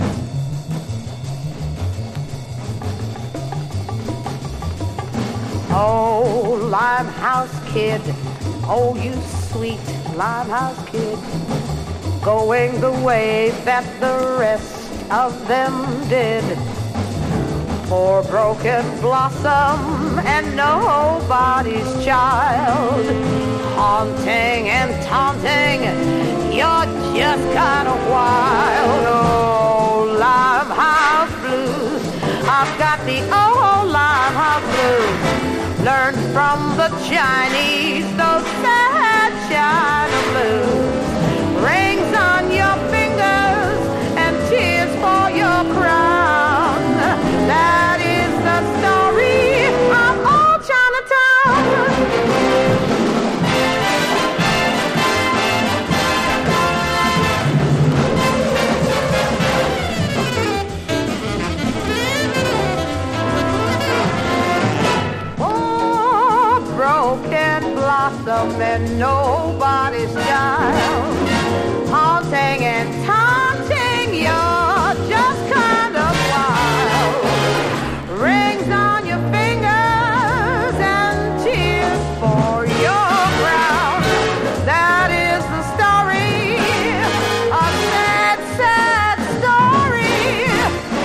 緩急ついた高速スウィング・アレンジの